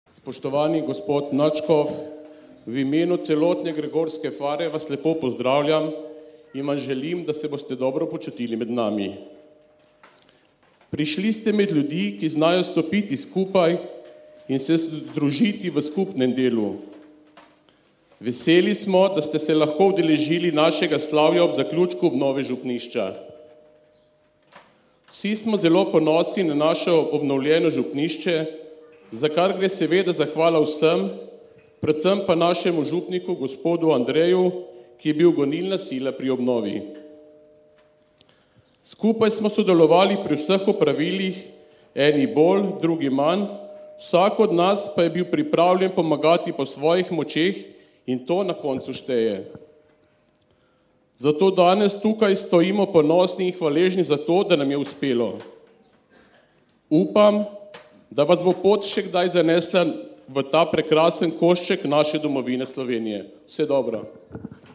SV. GREGOR (sobota, 9. julij 2016, RV) – Ljubljanski nadškof metropolit msgr. Stanislav Zore je nocoj na Sv. Gregorju pri Ortneku blagoslovil obnovljeno župnišče in gasilsko vozilo ter zatem daroval sveto mašo pod šotorom na igrišču.